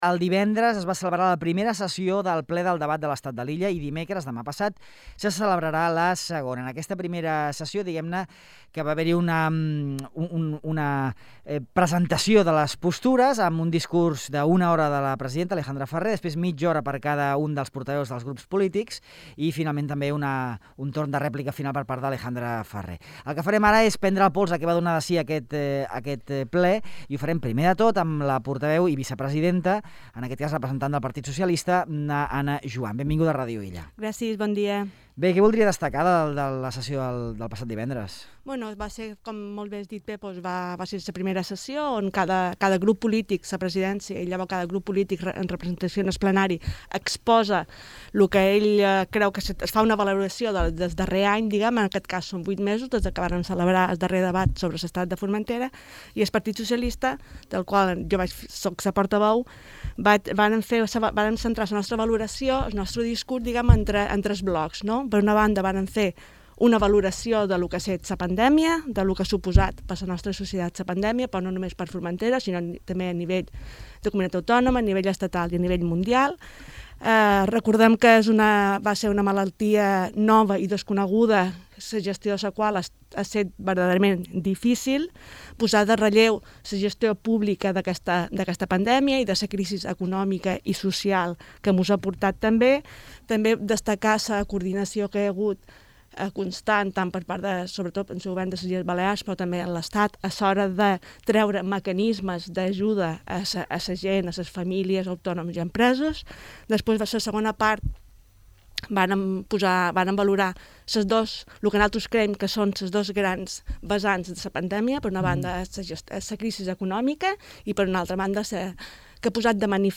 Ana Juan, del PSOE; Llorenç Córdoba, de Sa Unió; i Susana Labrador, de GxF; analitzen el que va donar de si la primera sessió del Ple de l’Estat de l’illa de Formentera, que se celebrà el passat divendres.